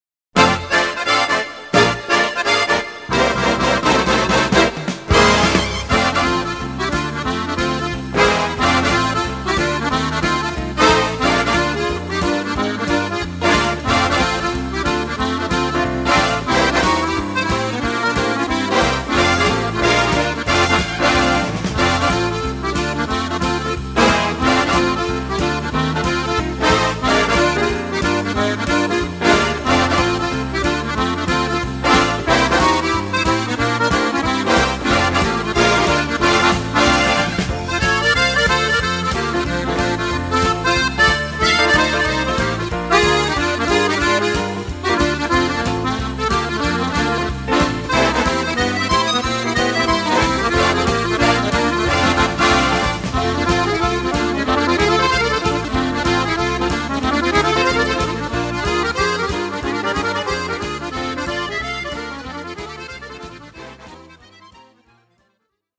accordéon musette - chant - clavier - bandonéon - sax